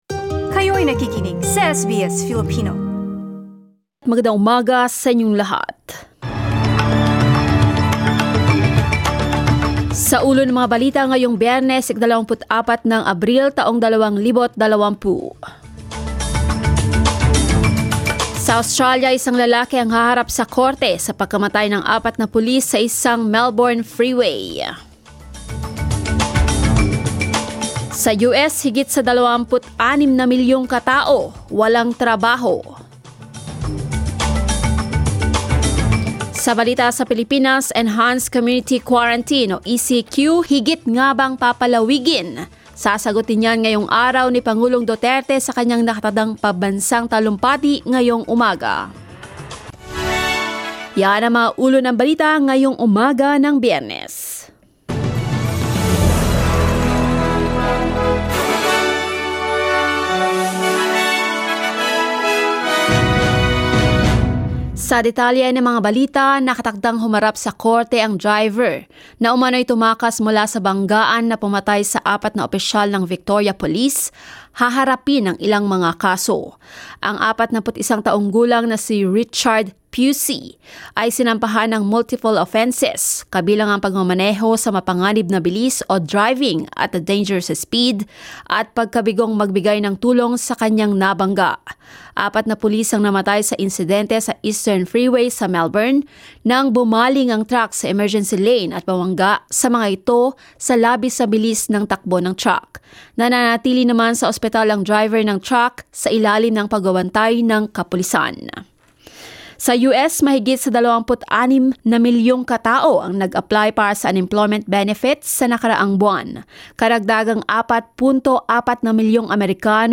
SBS News in Filipino, Friday 24 April